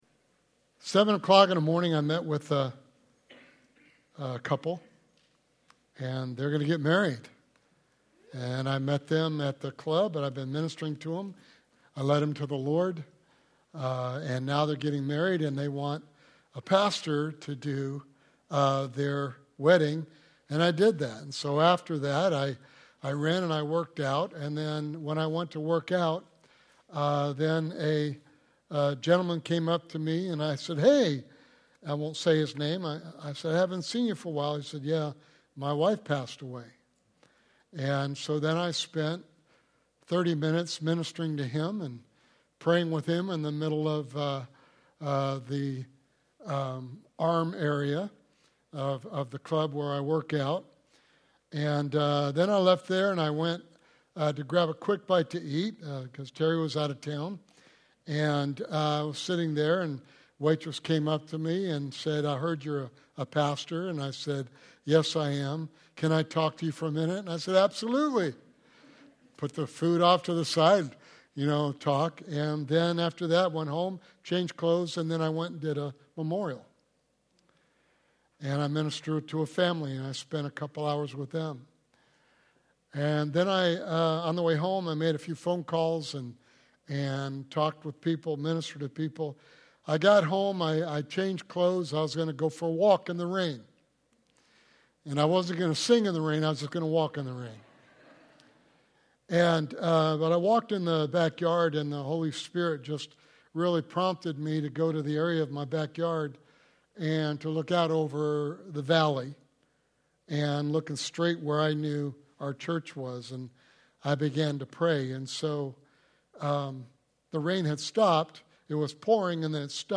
Sermon Series: Becoming the Better You